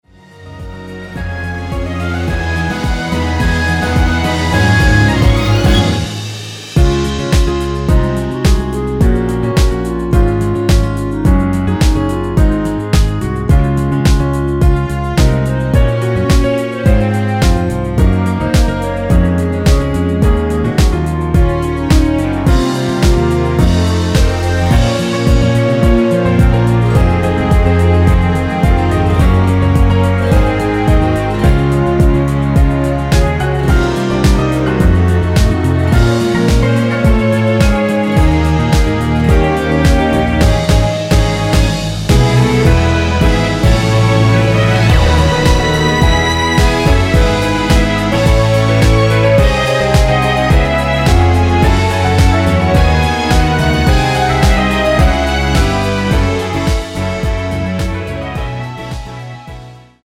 원키 멜로디 포함된 MR입니다.
Db
앞부분30초, 뒷부분30초씩 편집해서 올려 드리고 있습니다.
중간에 음이 끈어지고 다시 나오는 이유는